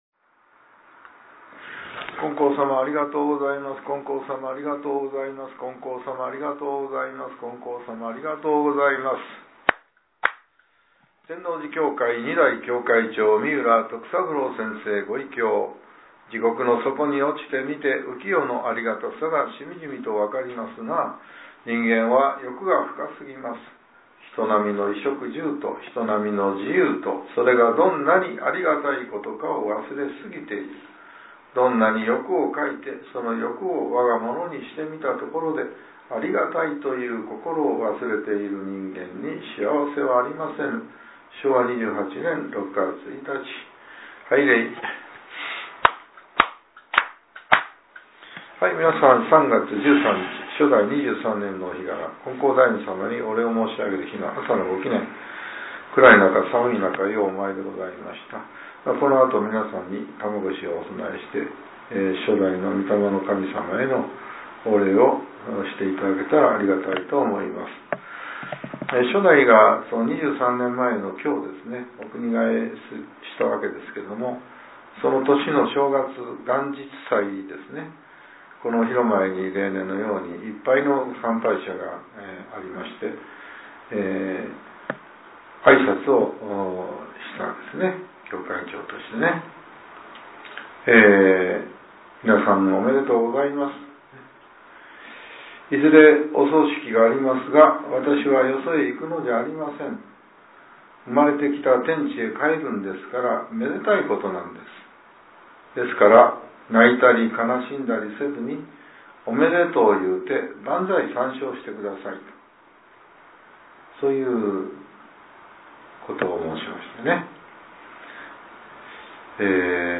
令和８年３月１３日（朝）のお話が、音声ブログとして更新させれています。